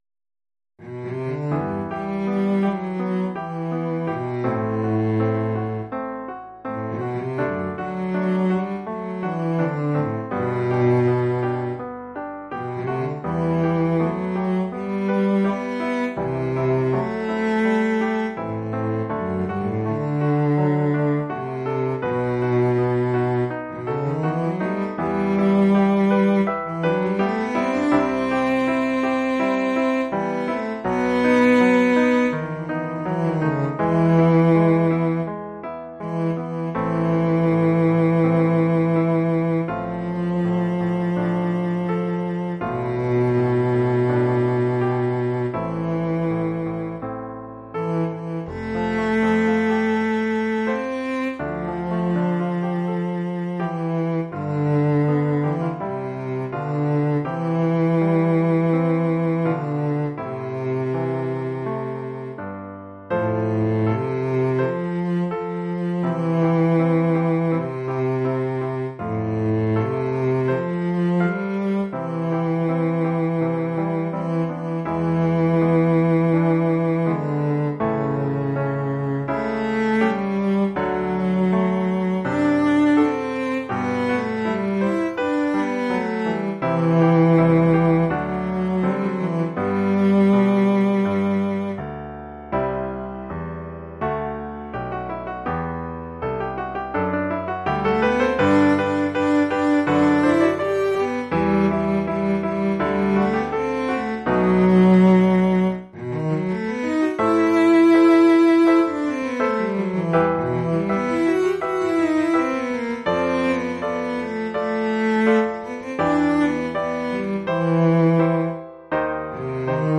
Formule instrumentale : Violoncelle et piano
Oeuvre pour violoncelle et piano.
Niveau : élémentaire.